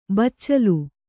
♪ baccalu